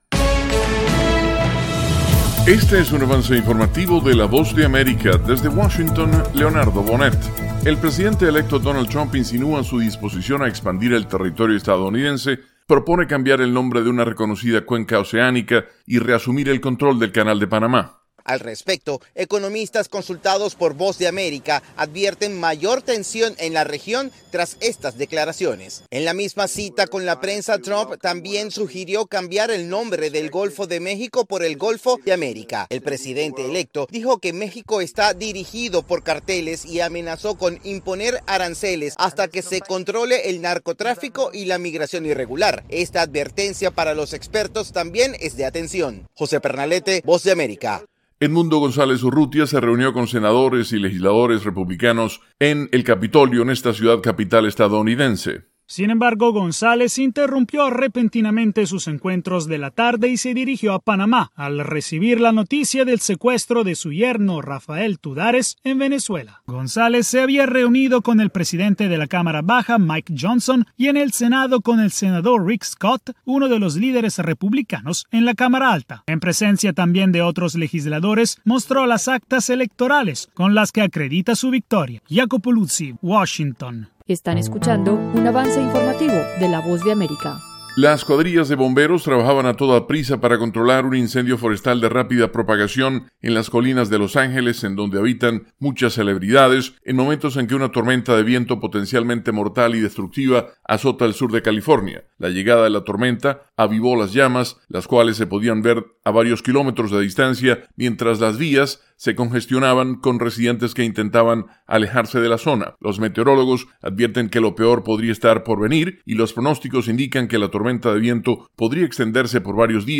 El siguiente es un avance informativo presentado por la Voz de América